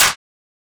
Clap (FuckYah).wav